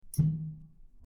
Cork Pop 02
Cork_pop_02.mp3